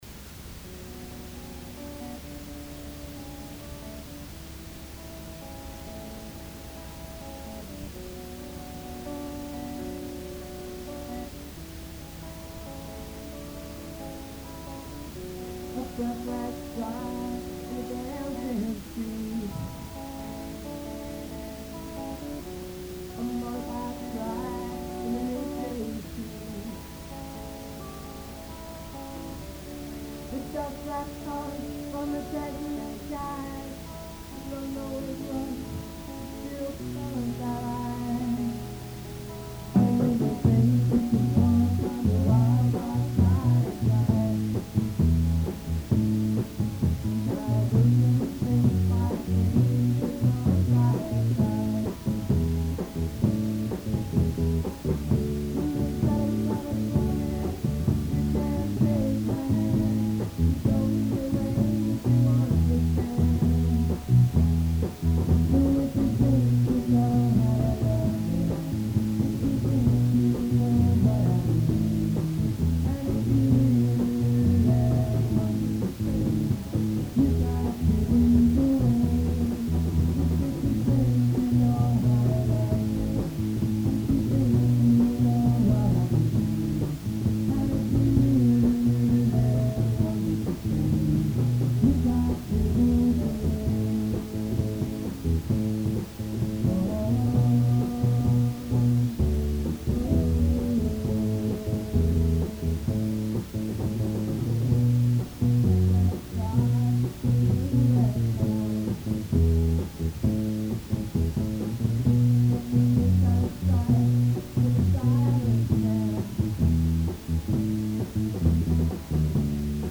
Lead Vocals and Guitar
Bass
Lead Vocals
Drums
Lead Guitar
Here are a few Orpheus covers recorded during a practice on Sunday 14th of June, 1976!: